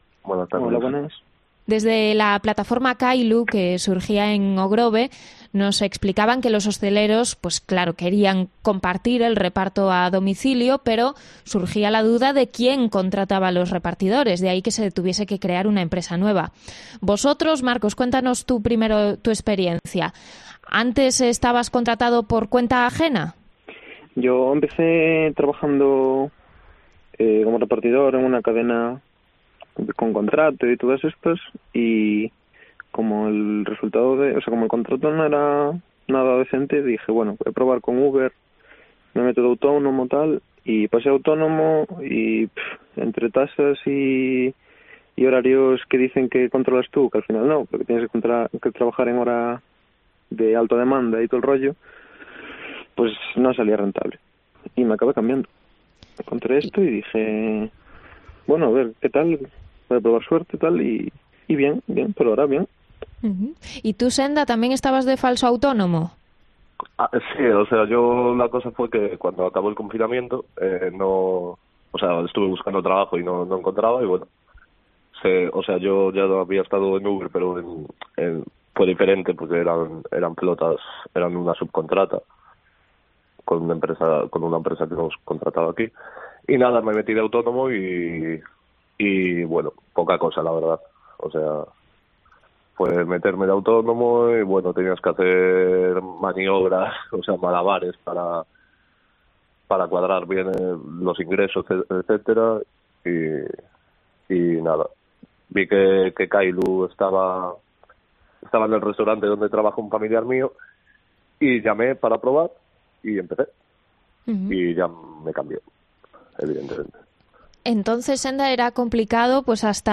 Entrevista a repartidores de comida a domicilio sobre sus condiciones laborales